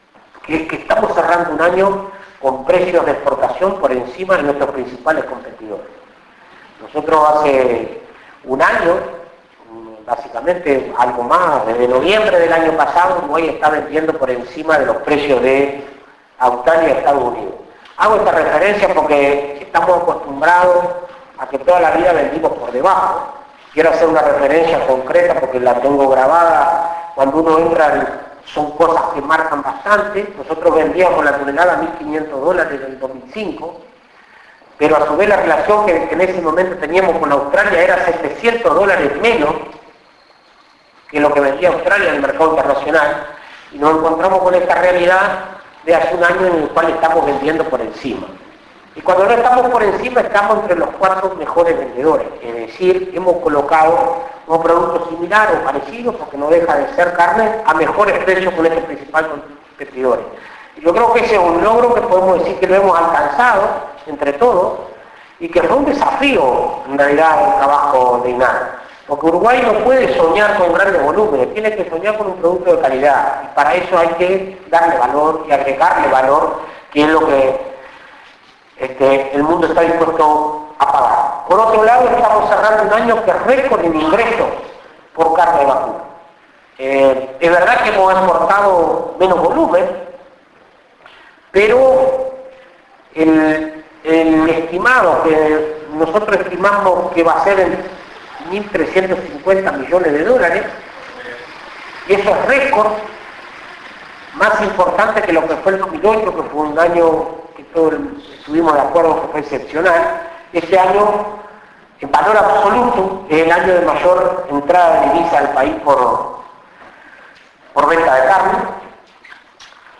Presidente de INAC Conferencia de prensa indicadores 2011 5:03 Mp3